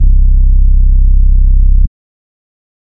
808 [ Metro House Sub ].wav